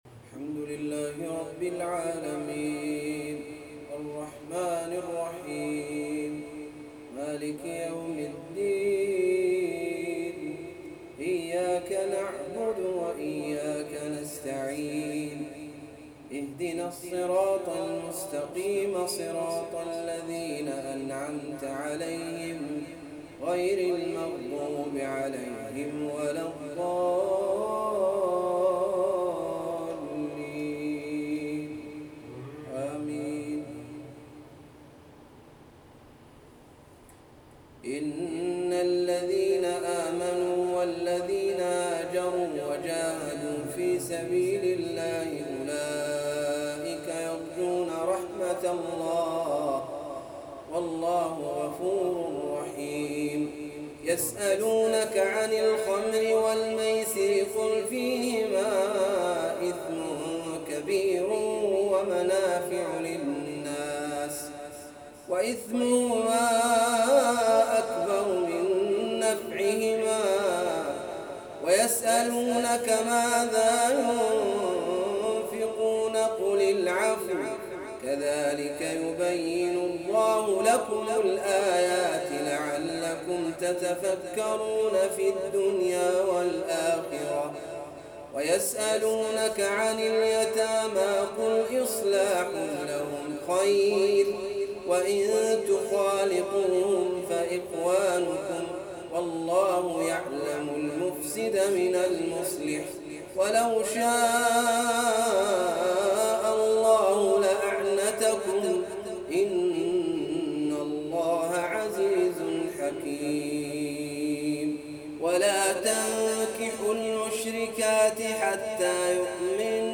تلاوة رستية بديعة على طريقة الجهني